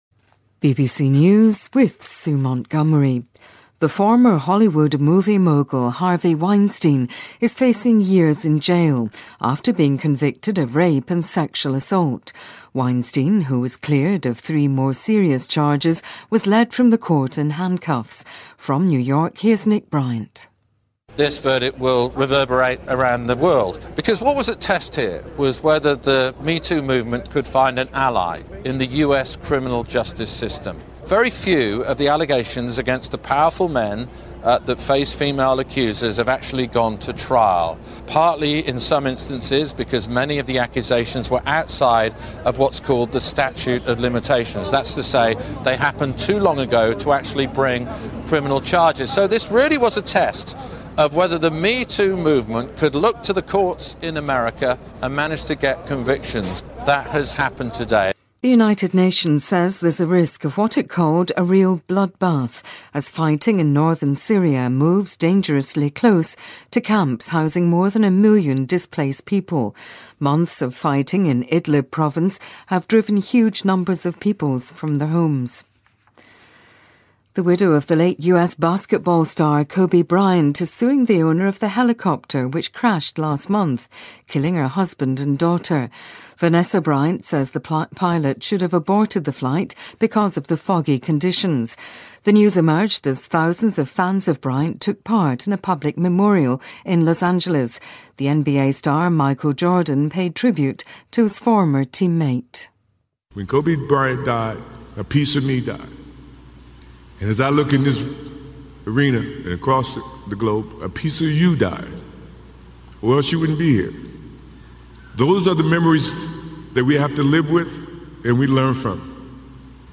英音听力讲解:科比公开追悼会在洛杉矶举行